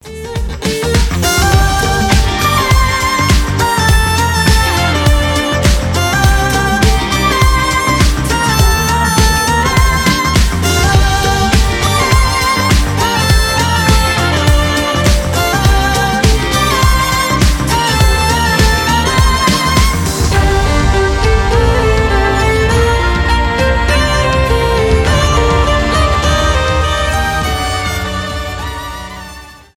танцевальные
скрипка
инструментальные